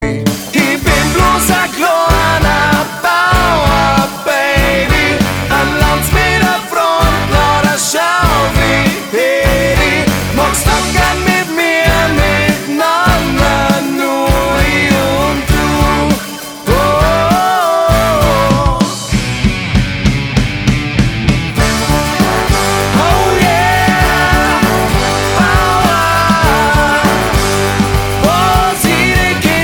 Gattung: Moderner Einzeltitel
Besetzung: Blasorchester
Die bayrische Coverversion
Tonart: C-Dur